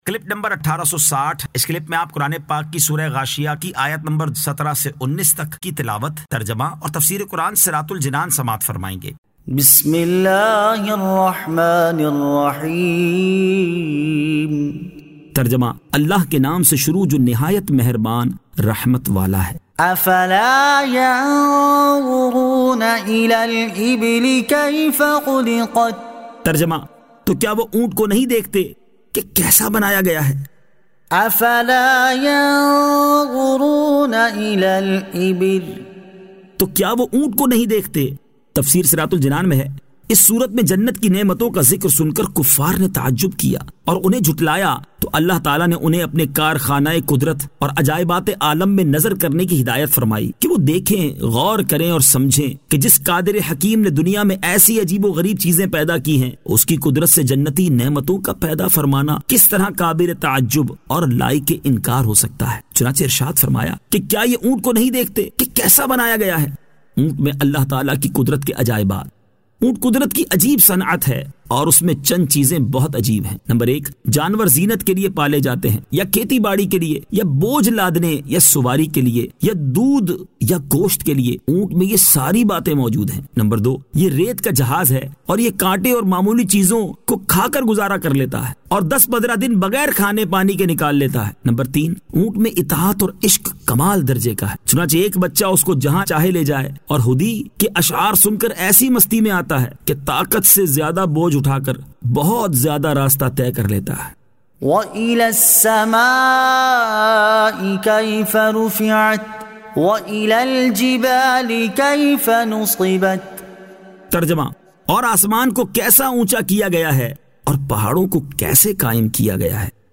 Surah Al-Ghashiyah 17 To 19 Tilawat , Tarjama , Tafseer